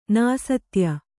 ♪ nāsatya